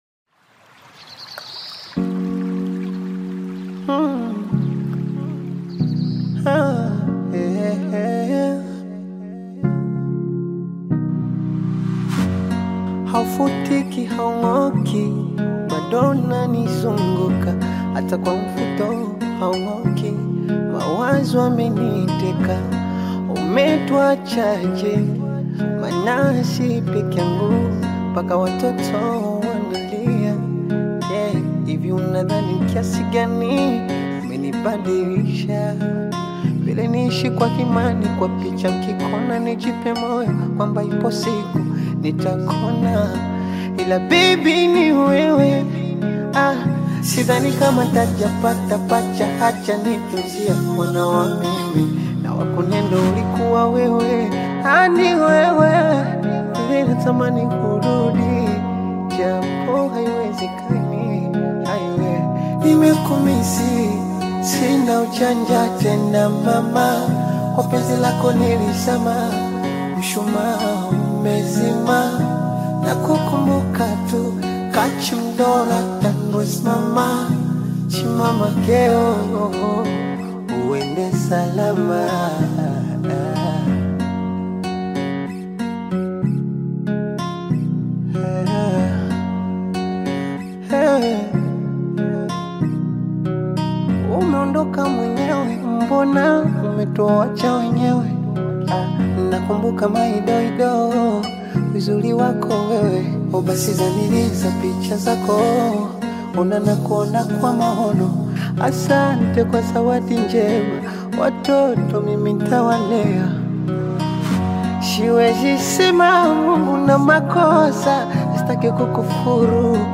Tanzanian artist
With soft production and a romantic atmosphere,
touching love song filled with emotion and replay value.